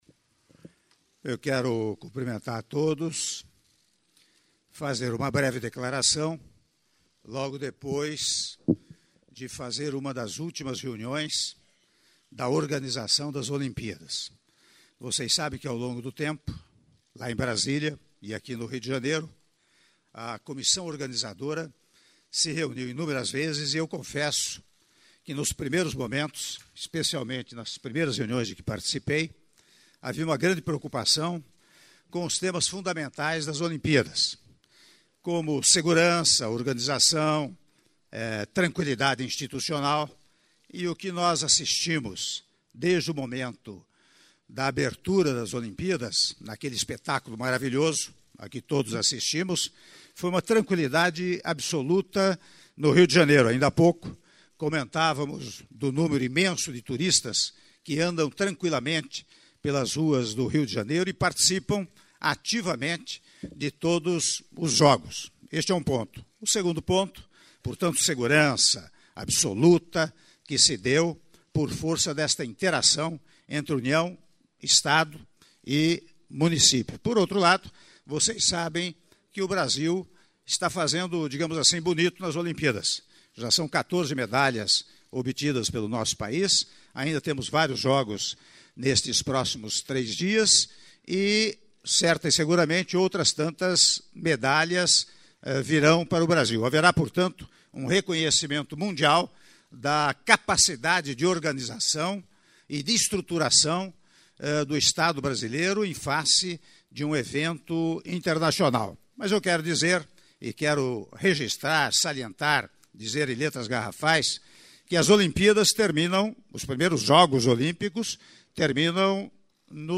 Áudio da declaração à imprensa do Senhor Presidente da República em exercício, Michel Temer, após reunião com ministros - Rio de Janeiro/RJ (02min41s)